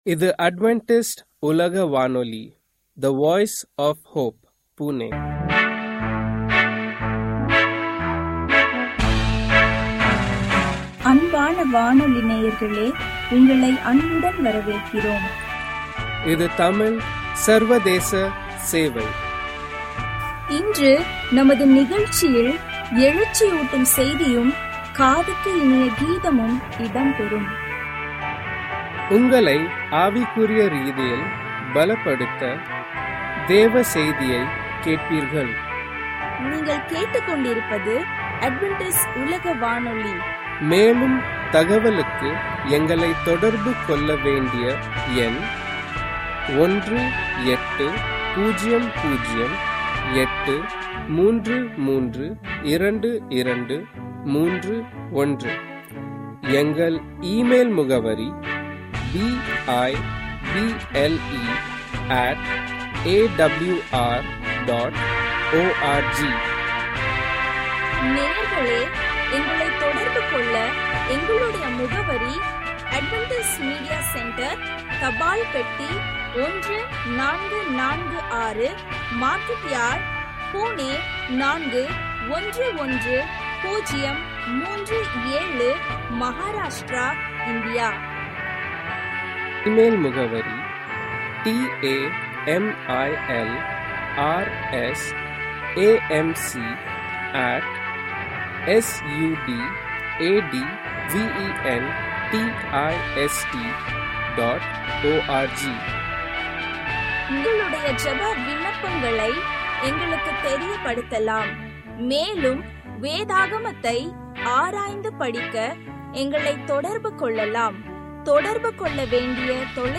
Tamil radio program from Adventist World Radio